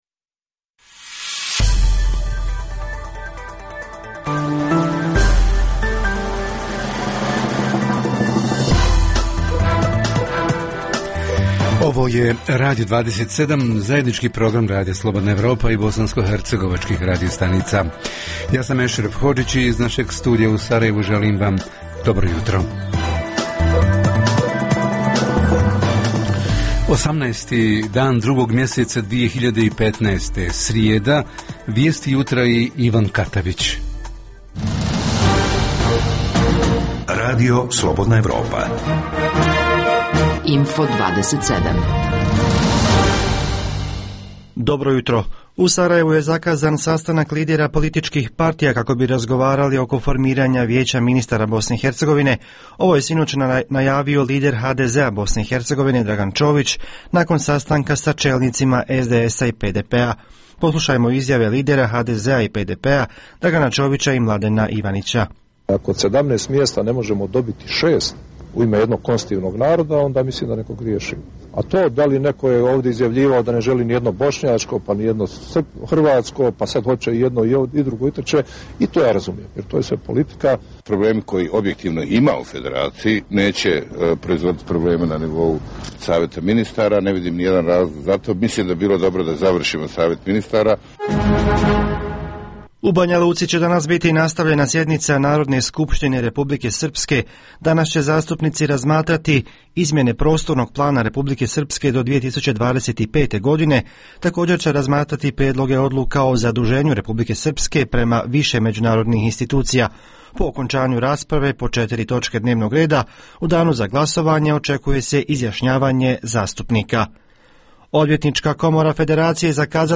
Jutarnji program za BiH koji se emituje uživo. Sadrži informacije, teme i analize o dešavanjima u BiH i regionu, a reporteri iz cijele BiH javljaju o najaktuelnijim događajima u njihovim sredinama.